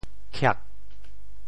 潮州发音 潮州 kiag4
khiak4.mp3